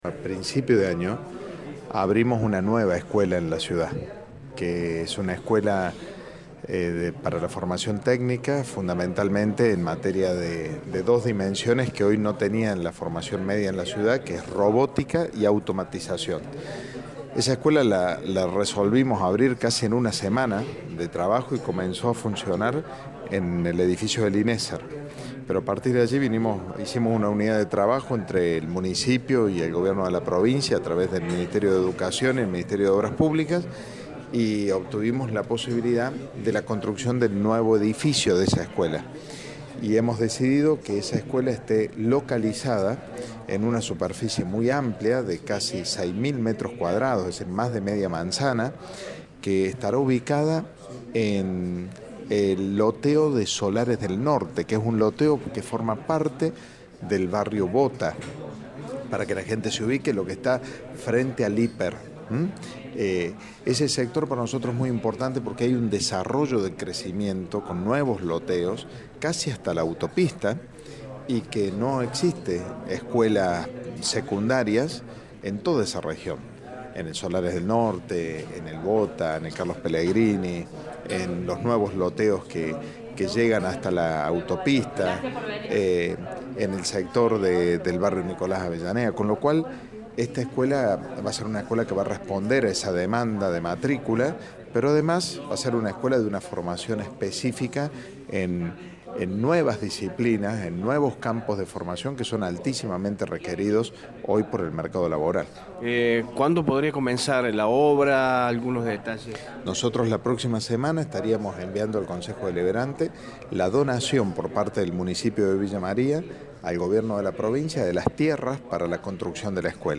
El Intendente de Villa Maria, Martín Gil, dialogó con nuestro medio y brindó detalles de la gran noticia.